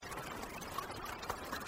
Rain free sound effects
Download this royalty free, public domain Rain sound effect, great for videogames and video editing.